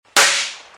Loud Shot Firework Pop Bouton sonore